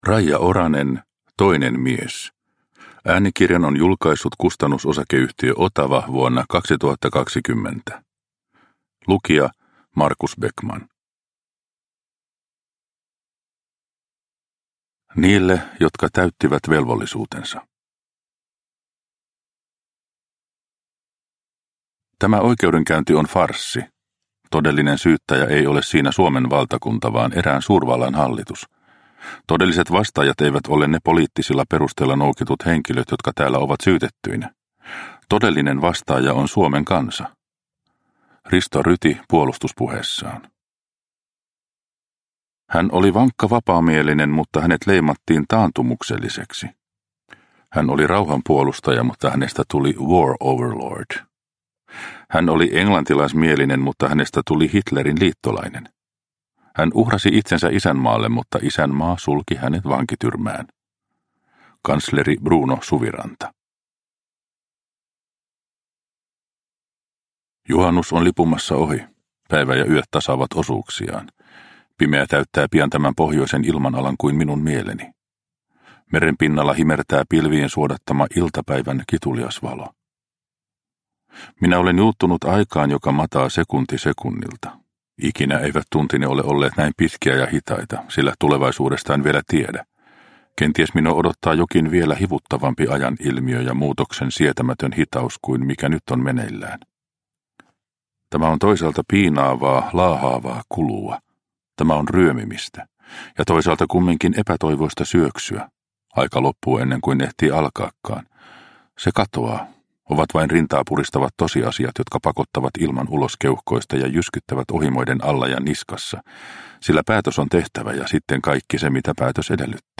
Toinen mies – Ljudbok – Laddas ner